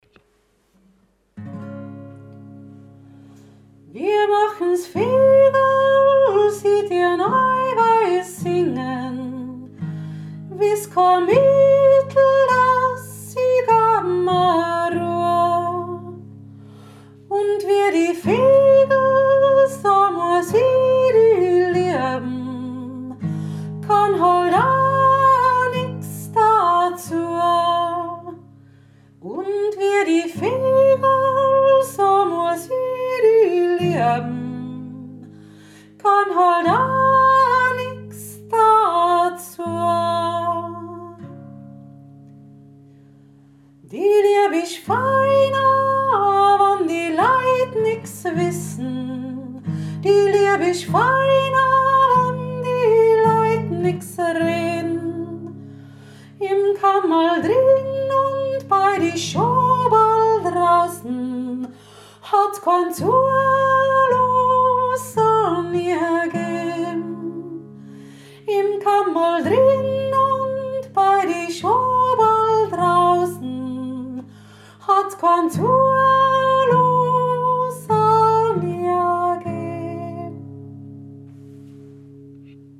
Zweite und dritte Strophe (zan zubisingen)